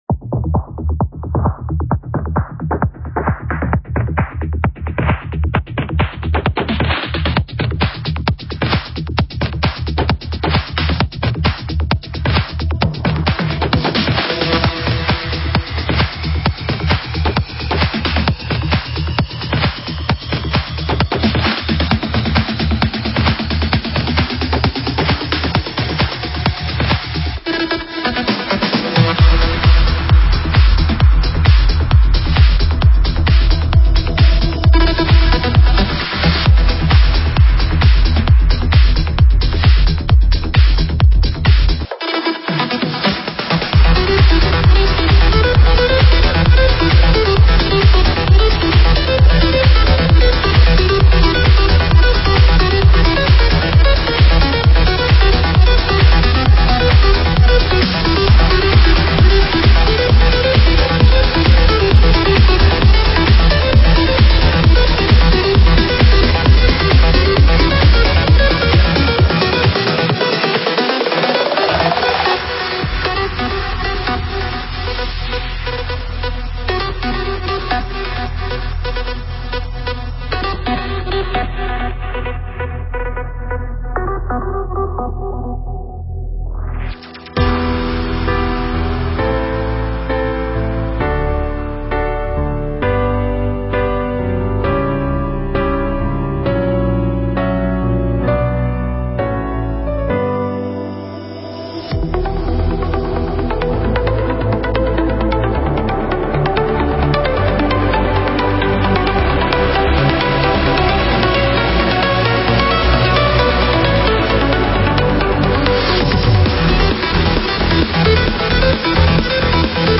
Стиль: Progressive Trance